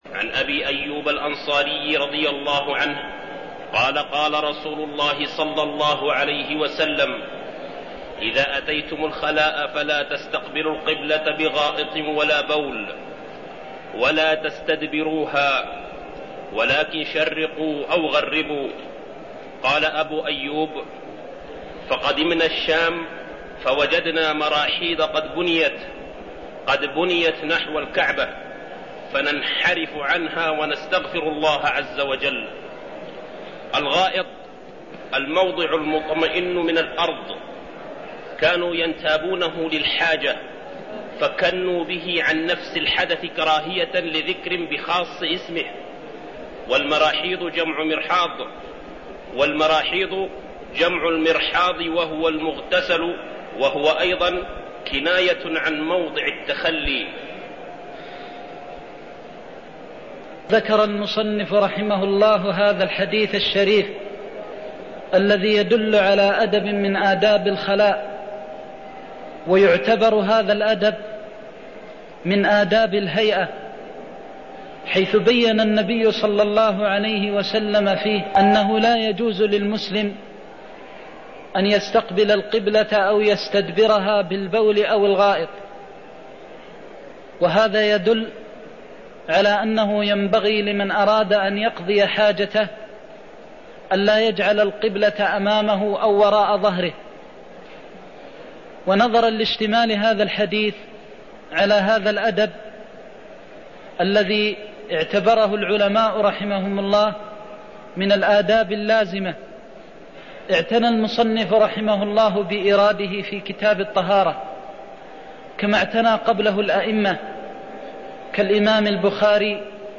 المكان: المسجد النبوي الشيخ: فضيلة الشيخ د. محمد بن محمد المختار فضيلة الشيخ د. محمد بن محمد المختار إذا أتيتم الغائط فلا تستقبلوا القبلة ولا تستدبروها (13)